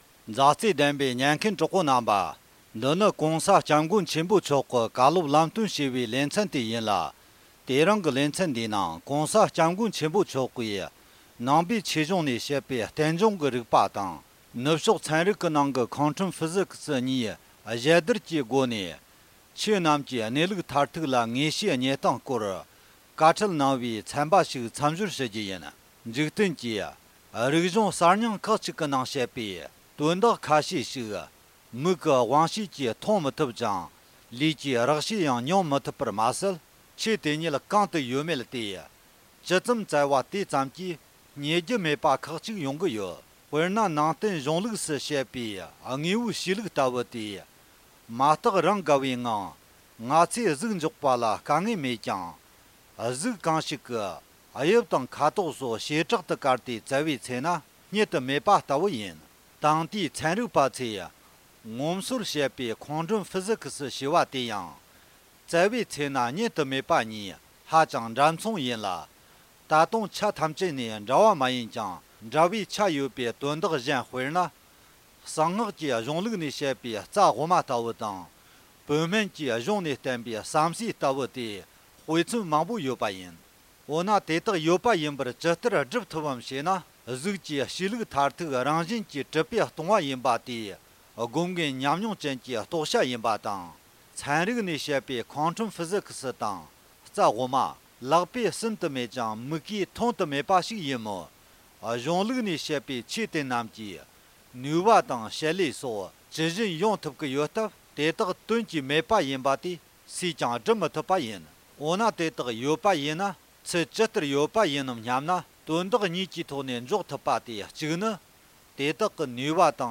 ༄༅། །༸གོང་ས་༸སྐྱབས་མགོན་ཆེན་པོ་མཆོག་གི་ལམ་སྟོན་བཀའ་སློབ་ཞེས་པའི་ལེ་ཚན་ནང་། ནང་ཆོས་ནང་གསུངས་པའི་རྟེན་འབྱུང་གི་རིག་པ་དང་། ནུབ་ཕྱོགས་ཚན་རིག་པའི་ཁོན་ཊམ་ཕི་སིཀས་(Quantum Physics) གཉིས་མཚུངས་བསྡུར་གྱི་སྒོ་ནས་ཆོས་རྣམས་ཀྱི་གནས་ལུགས་མཐར་ཐུག་ལ་ངེས་ཤེས་བརྙེས་སྟངས་སྐོར་བཀའ་སློབ་གནང་བ་ཞིག་སྙན་སྒྲོན་ཞུ་རྒྱུ་ཡིན།